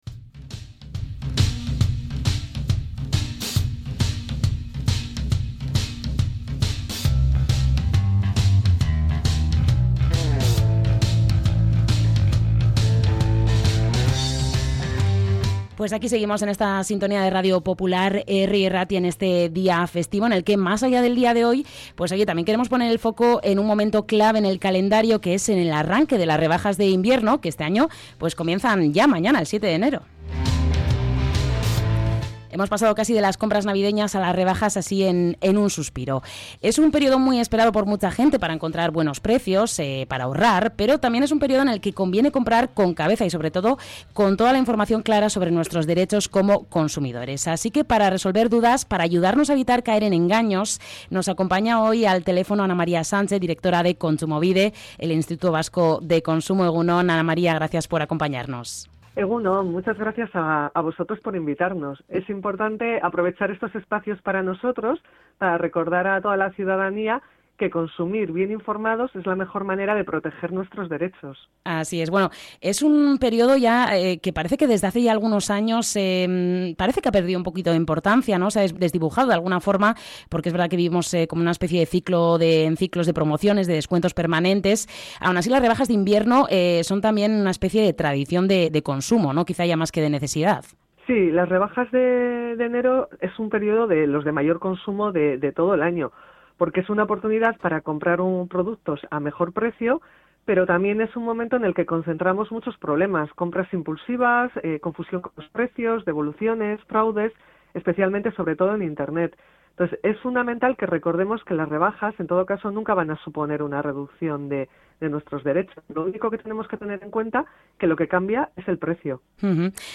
ENTREV.-REBAJAS-KONTSUMOBIDE-1.mp3